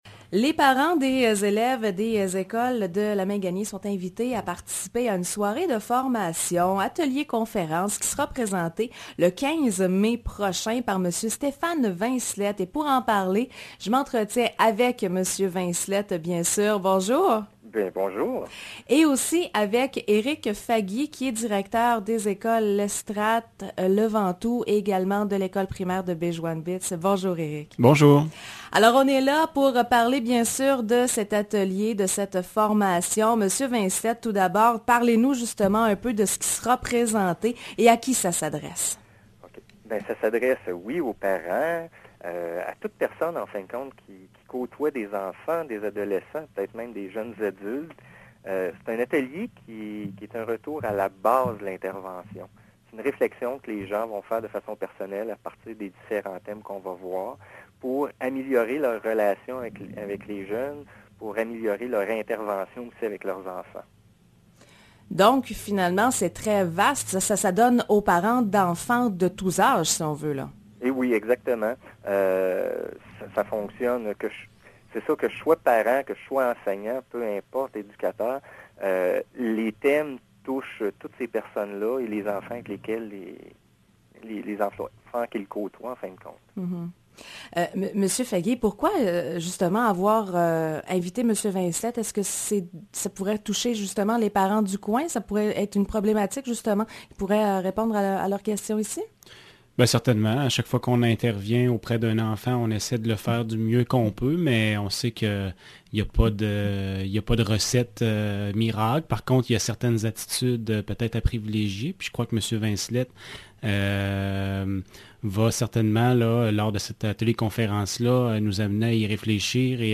ENTREVUES À LA RADIO: